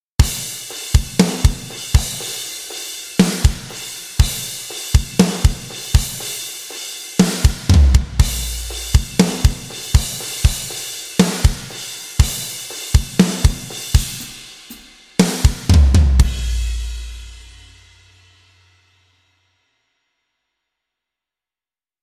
Качество: Моно 48 кГц 24 бита
Описание: Ударная установка
Gigantic low-tuned drums cut through with massive impact, while shimmering cymbals bring energy and brilliance, adding waves of polish and sheen.
Только ударные #2